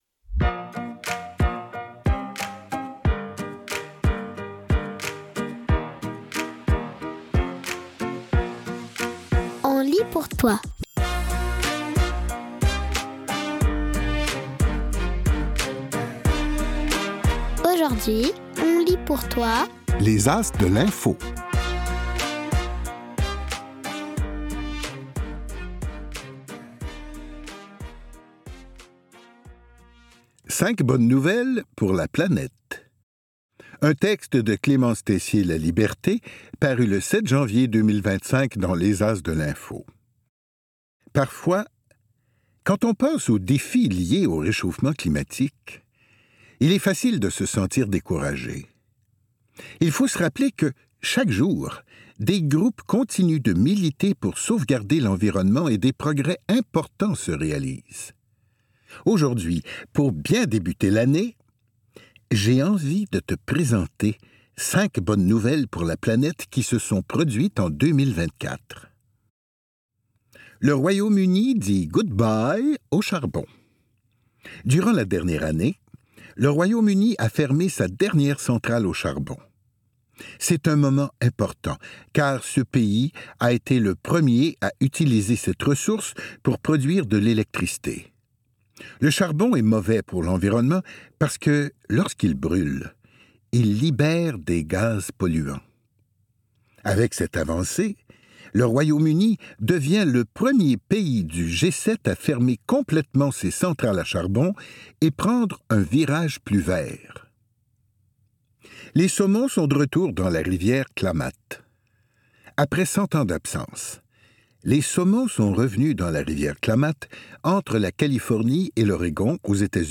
Images et mots, à voix haute! 5 bonnes nouvelles pour la planète!